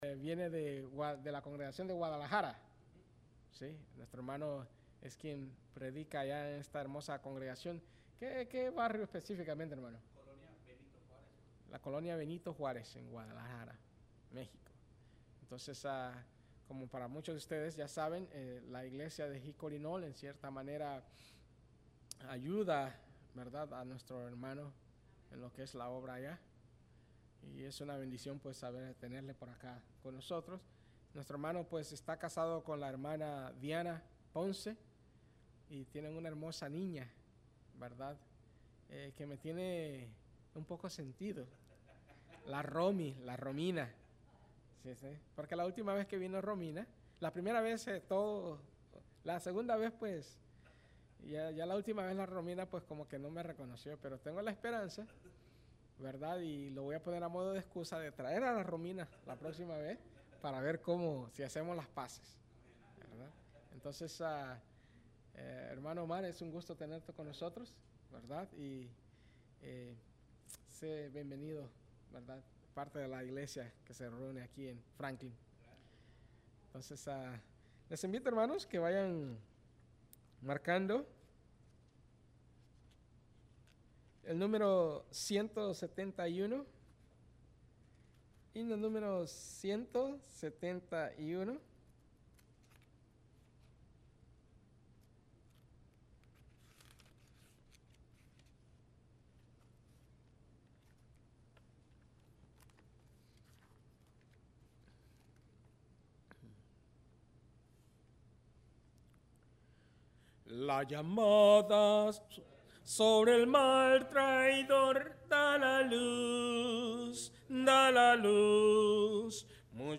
Thur bible class -Que aprendemos de la vida de pedro?